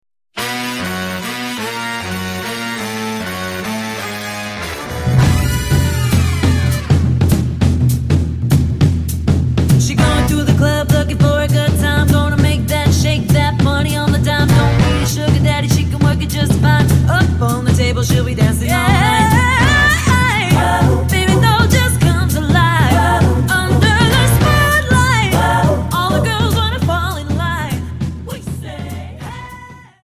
Dance: Quickstep 50 Song